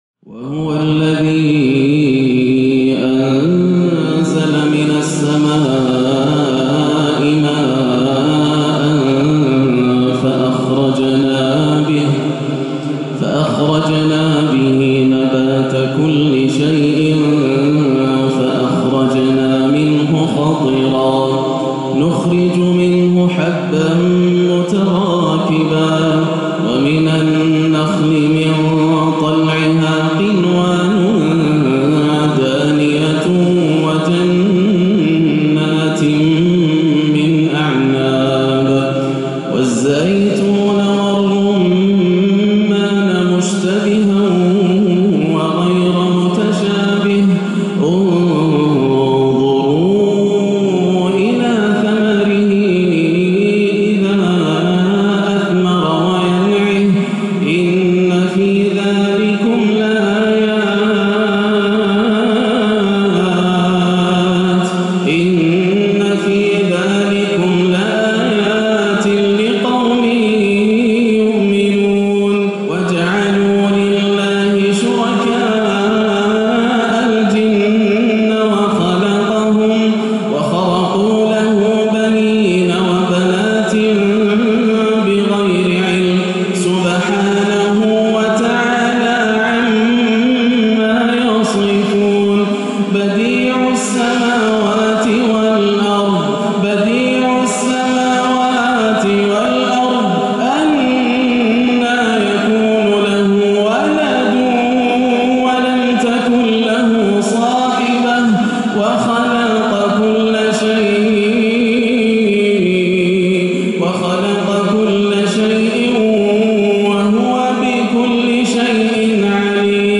(بَدِيعُ السَّمَاوَاتِ وَالْأَرْضِ ) قدرة الله وعظمتة تحيط بكل شي - تلاوة خاشعة جداً - عشاء السبت 4-5 > عام 1437 > الفروض - تلاوات ياسر الدوسري